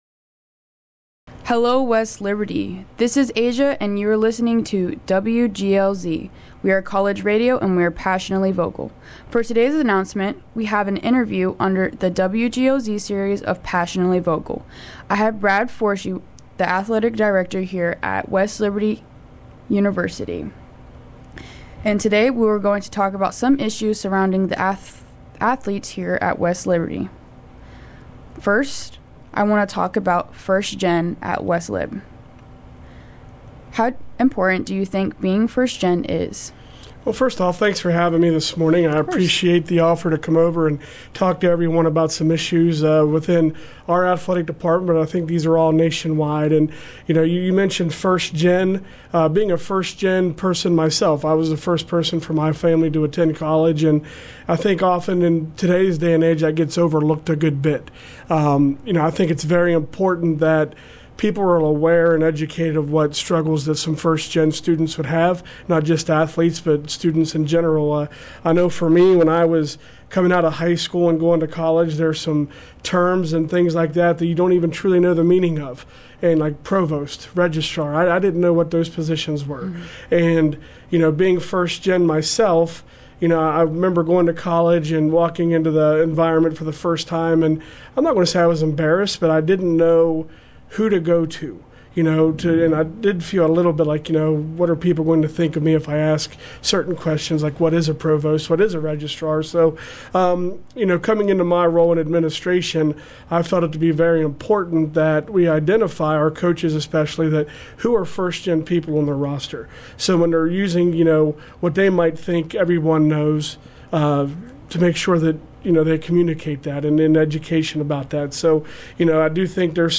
In the first part of this interview